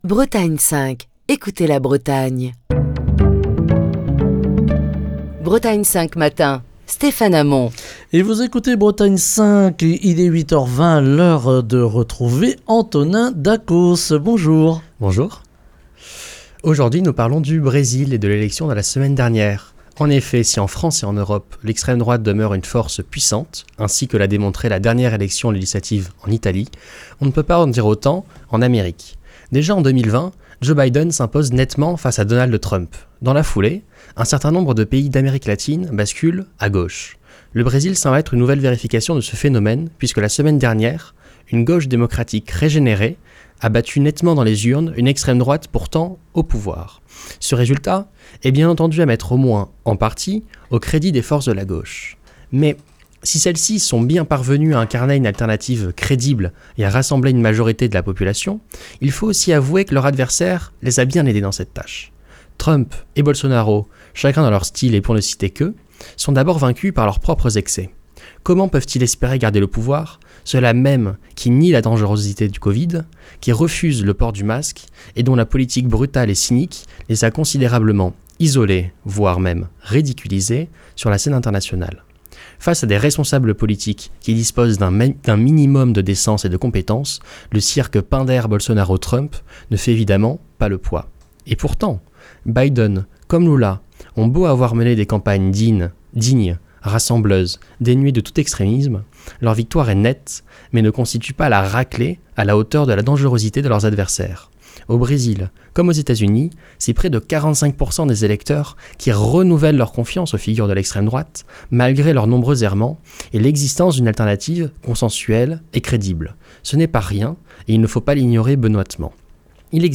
Chronique du 10 octobre 2022.